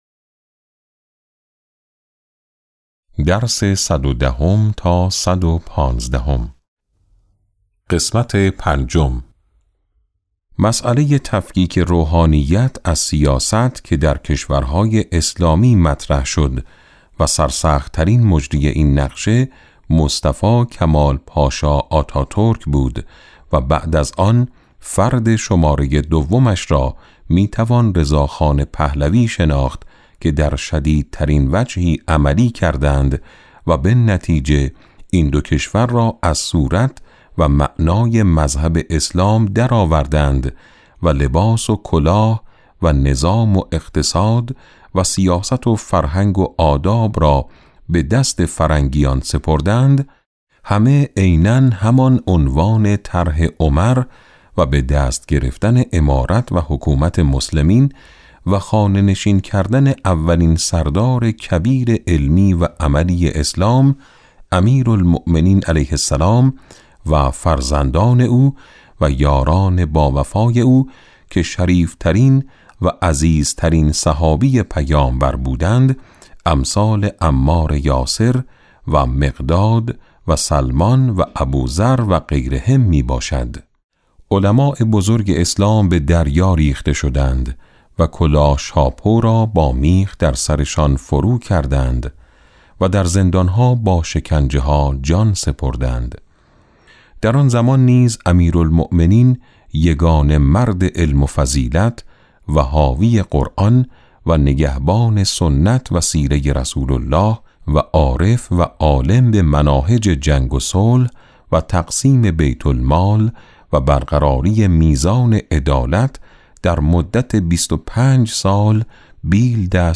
کتاب صوتی امام شناسی ج۸ - جلسه8